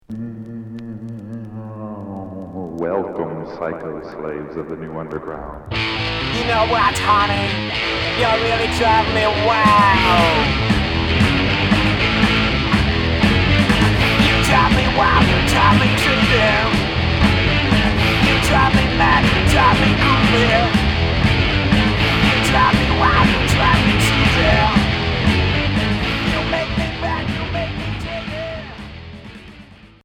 Garage psycho